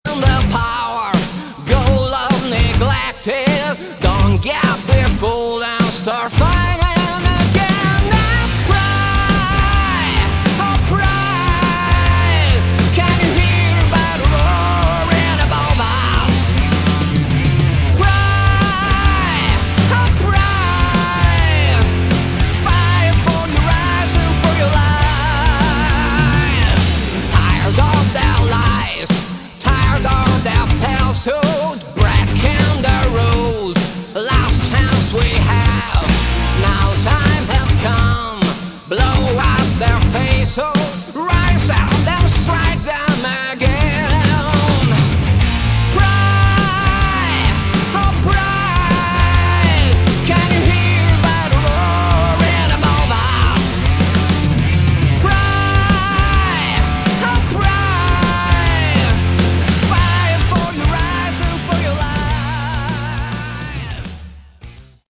Category: Melodic Metal
vocals
drums
guitar, bass